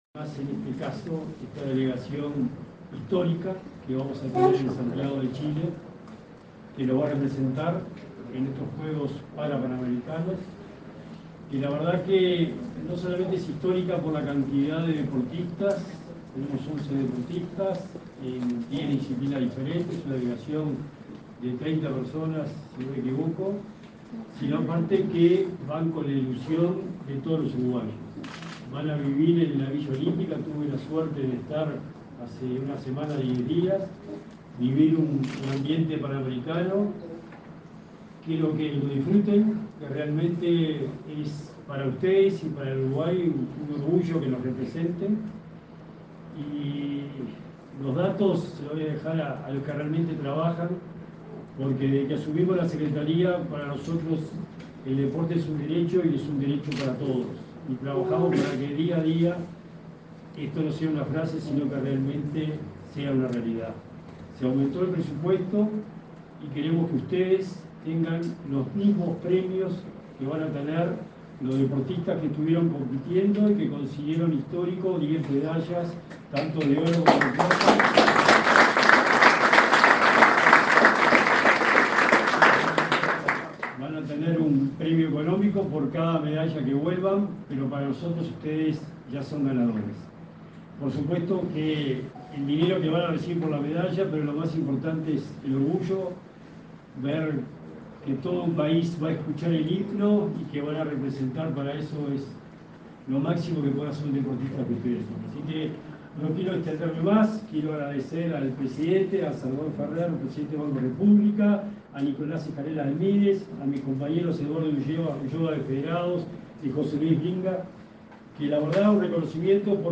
Acto de despedida y entrega de pabellón nacional a deportistas que participarán en los Juegos Parapanamericanos Santiago 2023